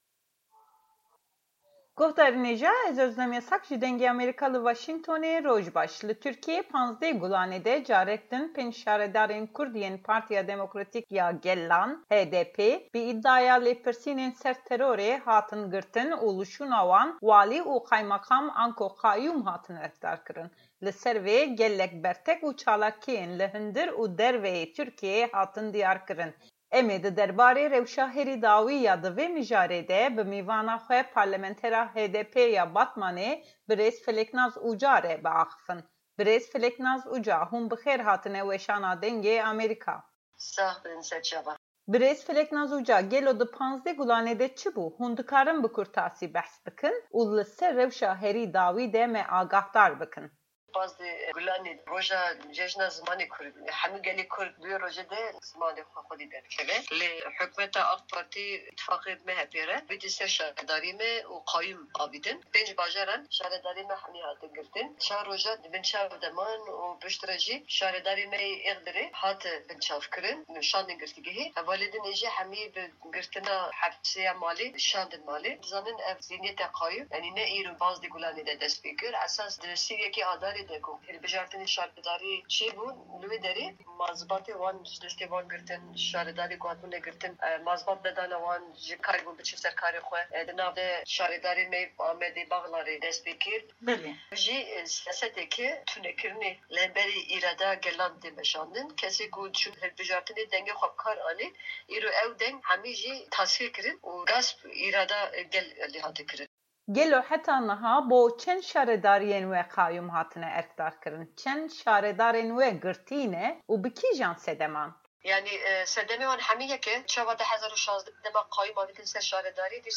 Hevpeyvina bi Parlementera HDp Feleknas Uca